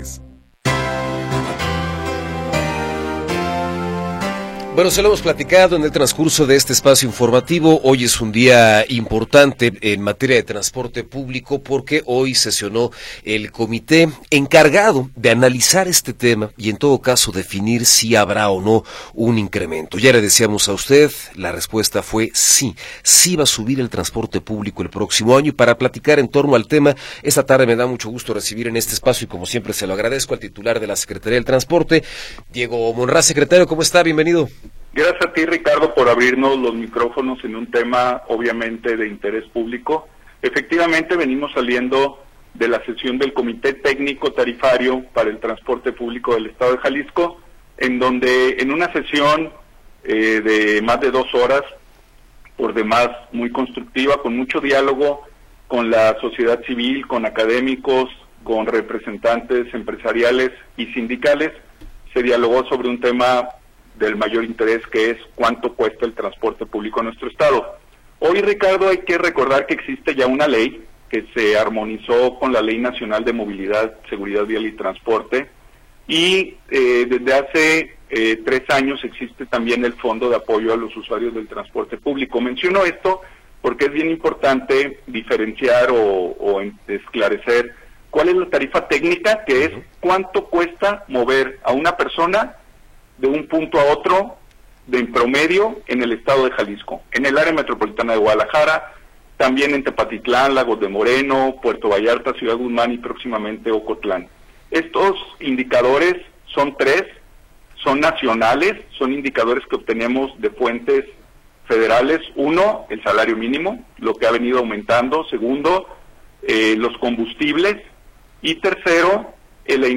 Entrevista con Diego Monraz Villaseñor